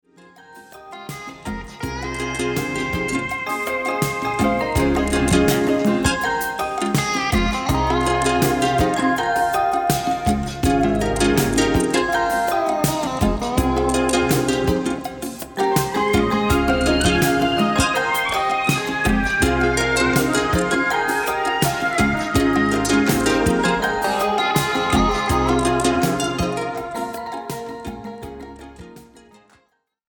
Recorded spring 1986 at the Sinus Studios, Bern Switzerland